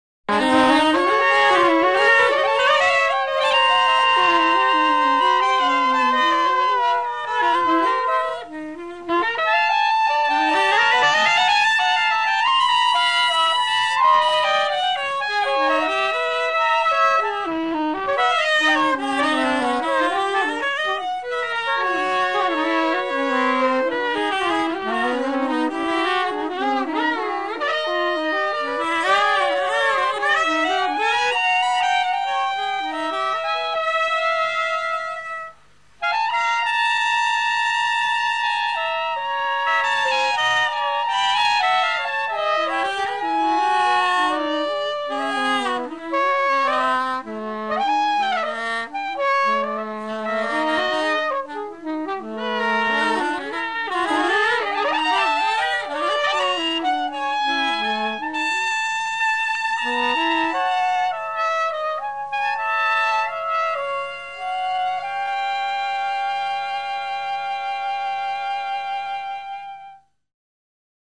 Sometimes barely audible, other times alarmingly loud
sometimes very moody and dreamy